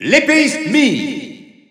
Announcer pronouncing Mii Swordfighter in French.
Mii_Swordfighter_French_Alt_Announcer_SSBU.wav